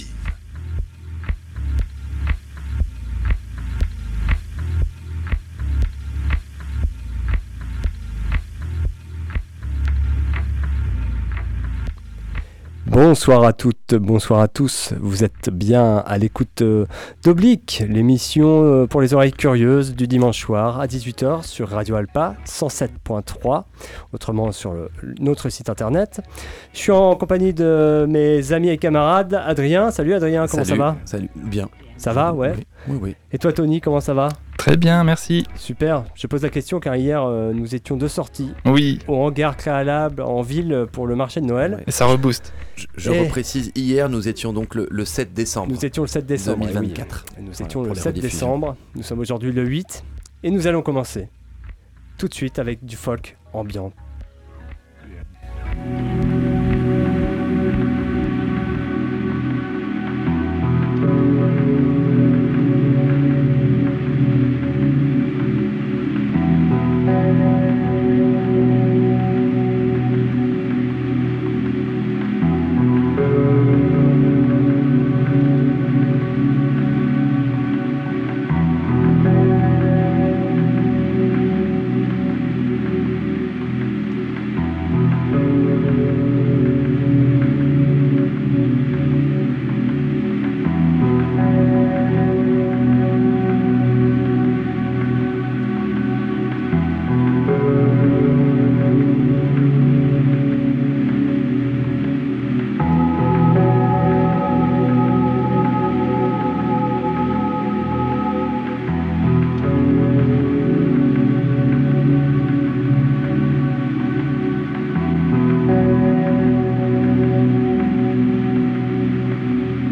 CHILL ELECTRO